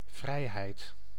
Ääntäminen
IPA : /ˈlɪbɚti/